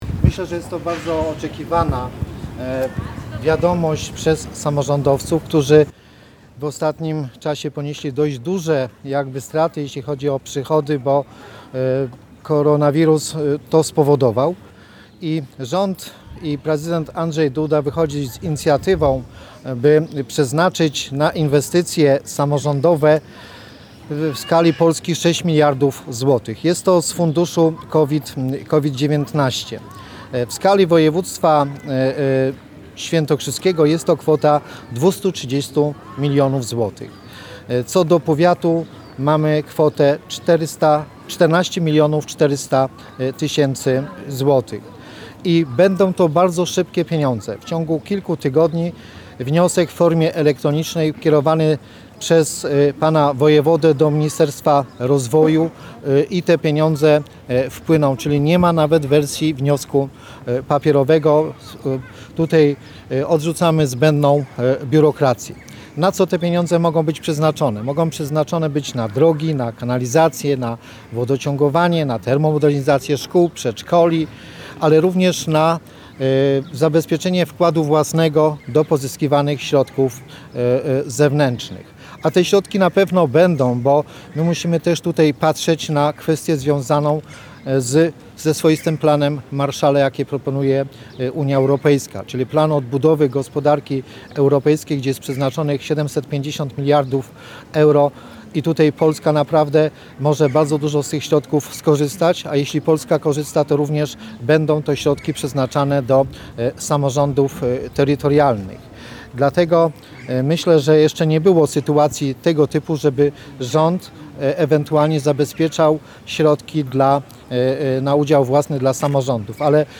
Mówi poseł Marek Kwitek, który przekazał wójtom i burmistrzom symboliczne czeki: